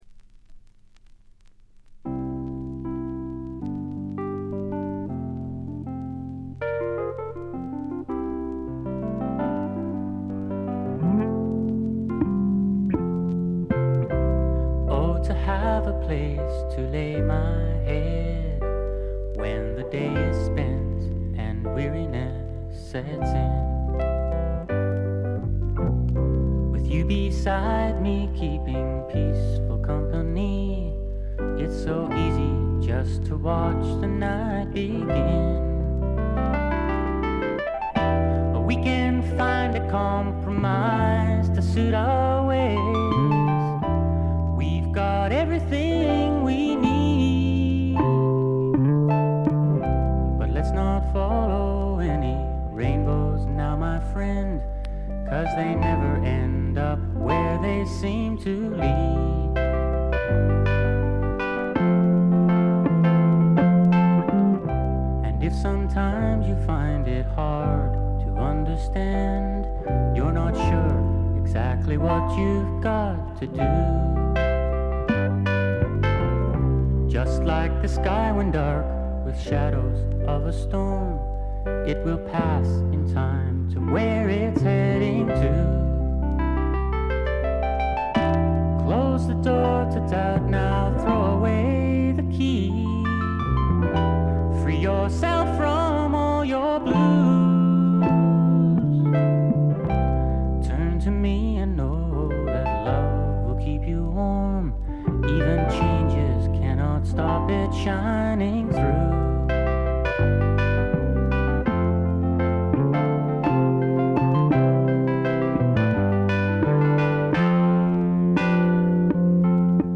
部分試聴ですが軽微なバックグラウンドノイズ程度。
試聴曲は現品からの取り込み音源です。